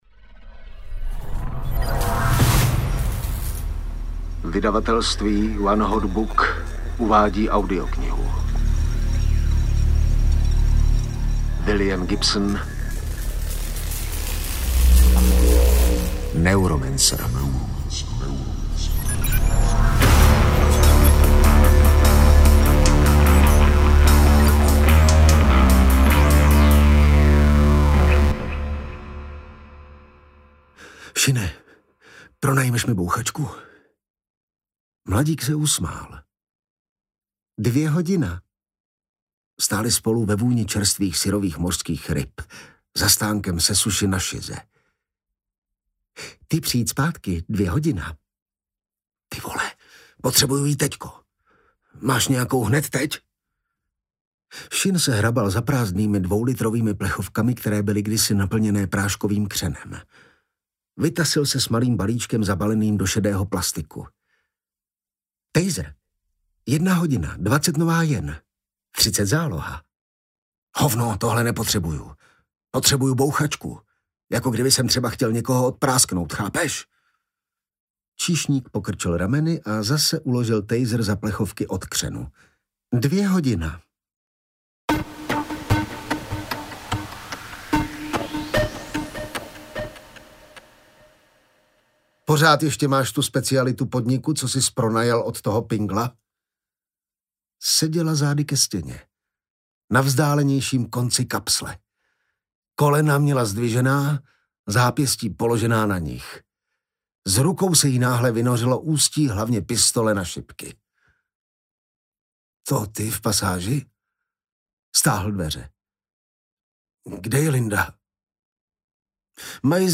Neuromancer audiokniha
Ukázka z knihy
neuromancer-audiokniha